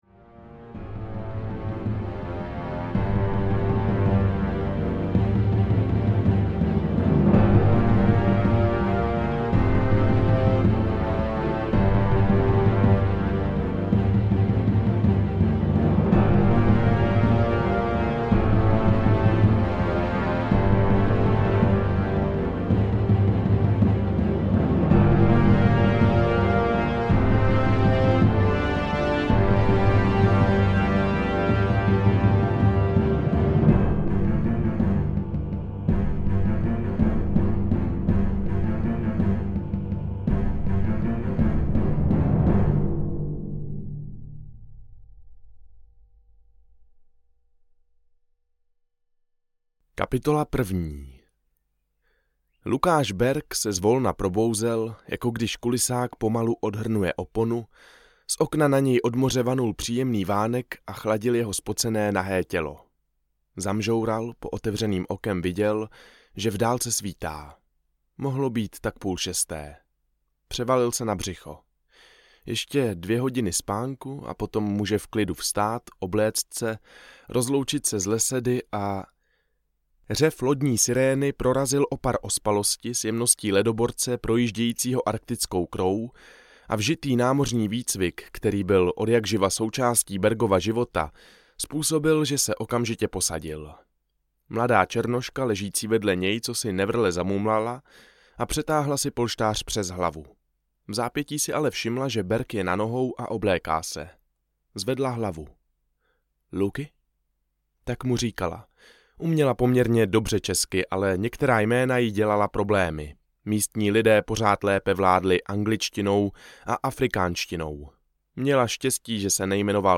Ofenziva českých zemí audiokniha
Ukázka z knihy